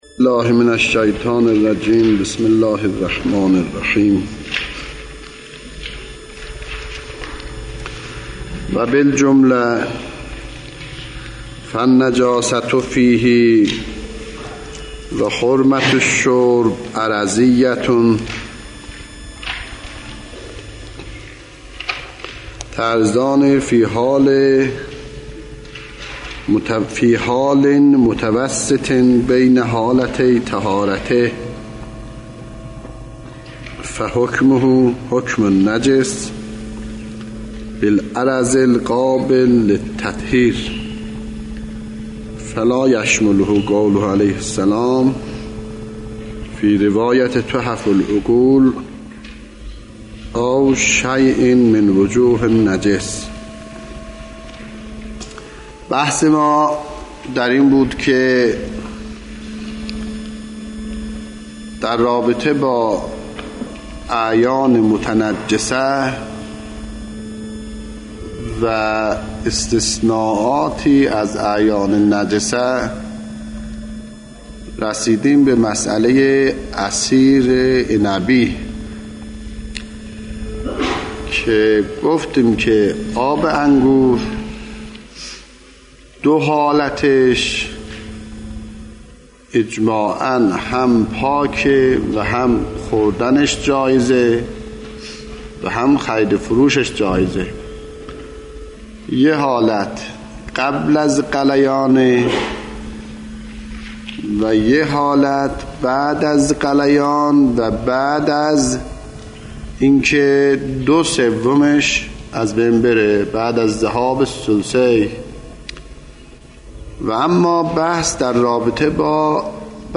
مکاسب محرمه | مرجع دانلود دروس صوتی حوزه علمیه دفتر تبلیغات اسلامی قم- بیان